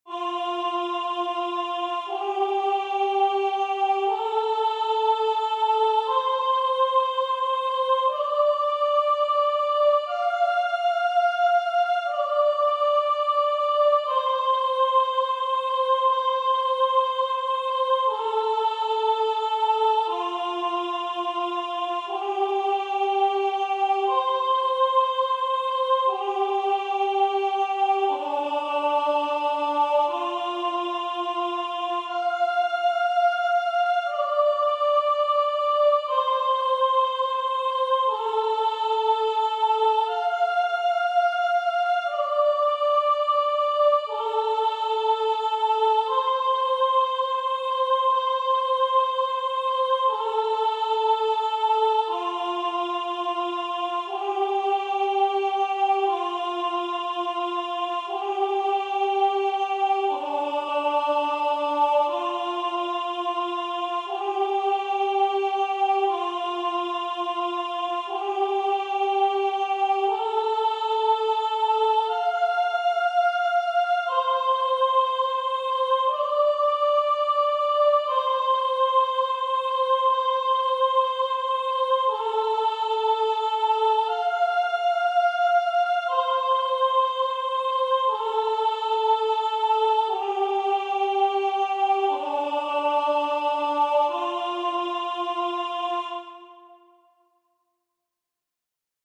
Here is an example of an ancient hymn in the pentatonic scale: